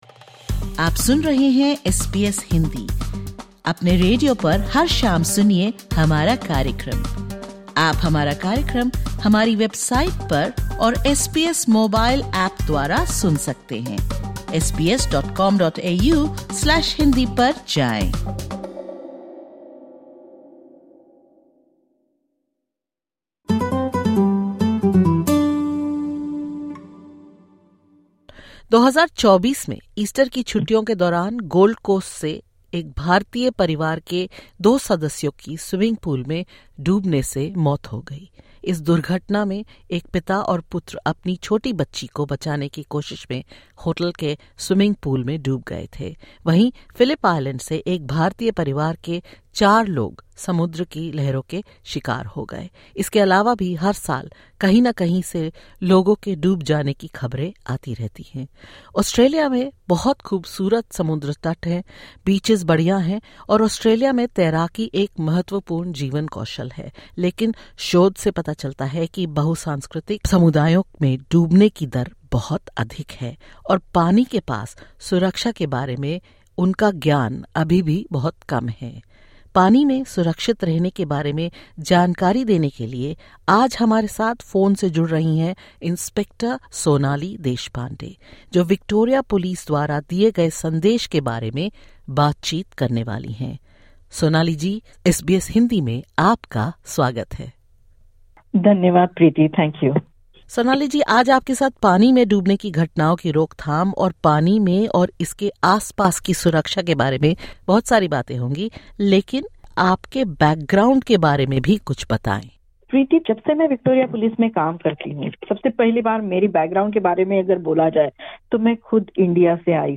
Disclaimer: The information given in this interview is of general nature.